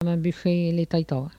Langue Maraîchin
Patois - archives